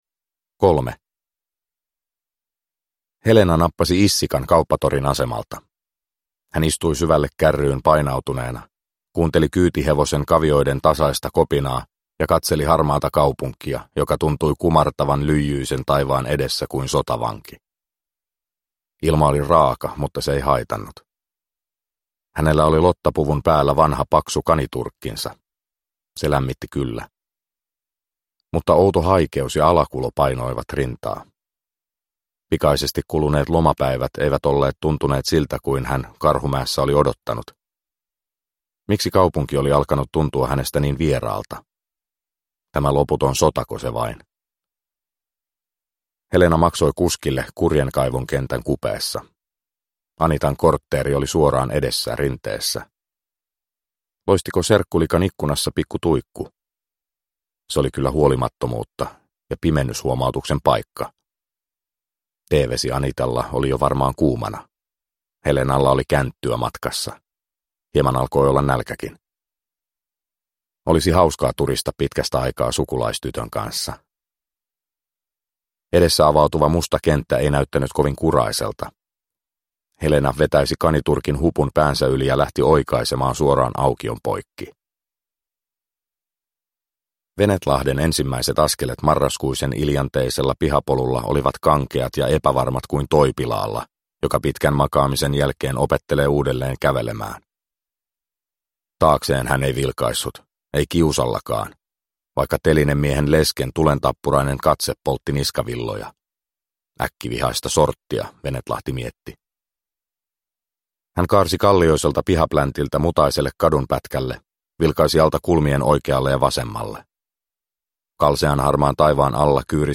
Sahanpururevolveri 4 – Ljudbok – Laddas ner